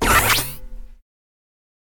teleporter.ogg